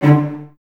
Index of /90_sSampleCDs/Miroslav Vitous - String Ensembles/Cellos/CES Stacc